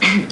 Cough (female) Sound Effect
Download a high-quality cough (female) sound effect.
cough-female-1.mp3